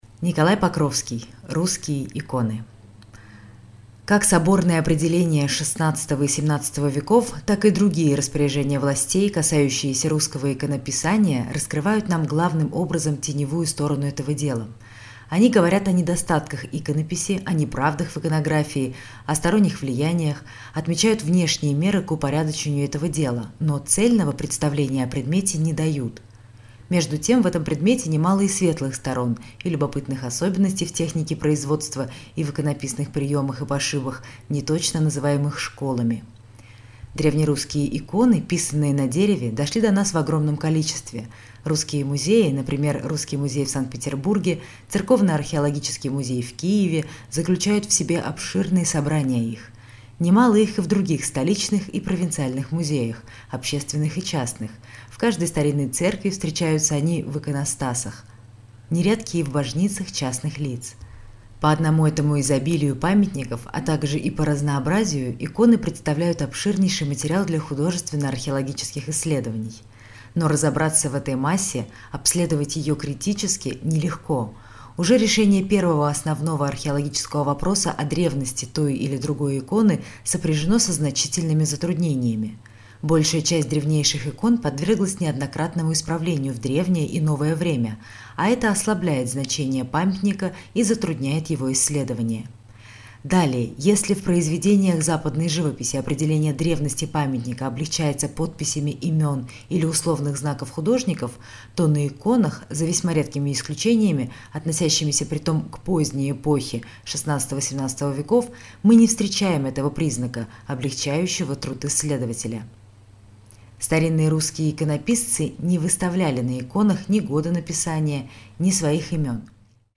Aудиокнига Русские иконы Автор Николай Покровский Читает аудиокнигу Татьяна Веденеева.